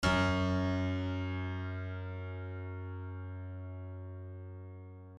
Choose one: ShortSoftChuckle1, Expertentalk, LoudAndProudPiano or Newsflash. LoudAndProudPiano